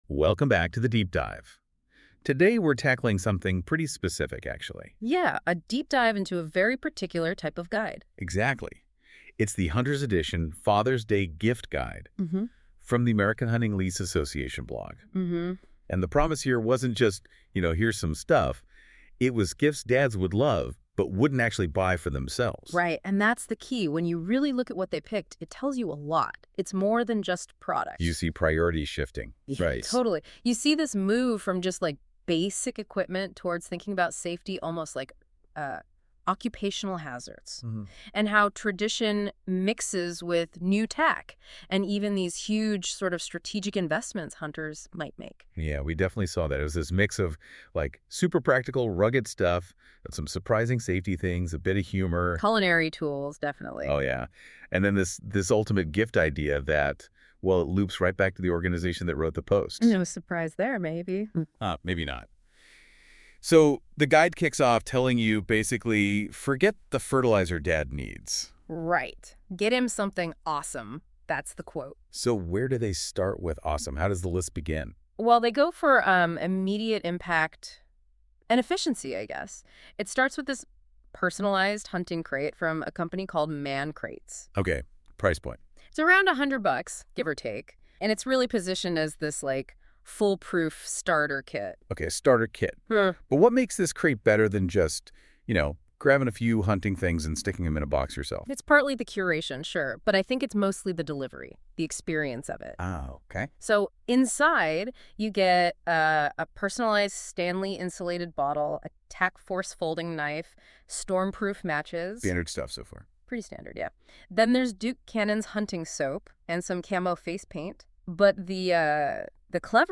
AI generated summary The source provides a critical analysis of a Father's Day gift guide released by the American Hunting Lease Association (AHLA), documenting how the recommended products map out the priorities of the modern sportsman.